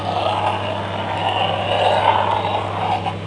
sliding.wav